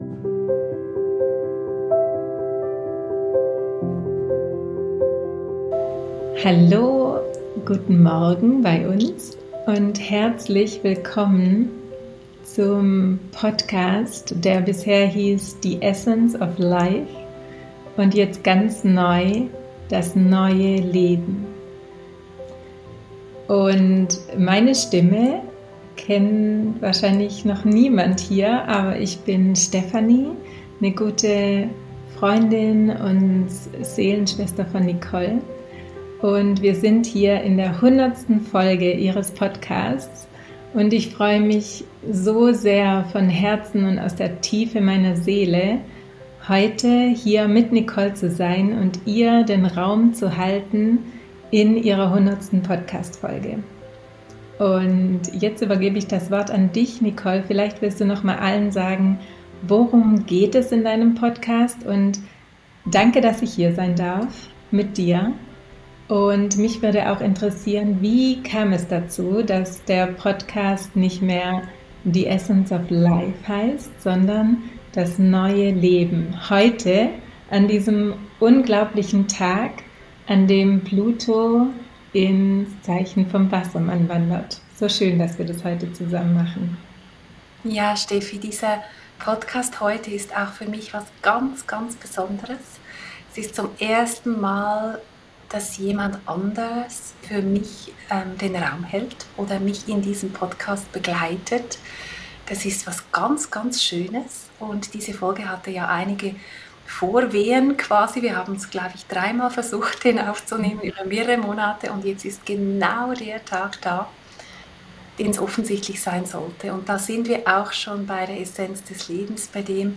In der 100. Podcastfolge bin ich zu Gast in meinem eigenen Podcast!